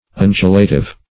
Search Result for " undulative" : The Collaborative International Dictionary of English v.0.48: Undulative \Un"du*la*tive\, a. Consisting in, or accompanied by, undulations; undulatory.
undulative.mp3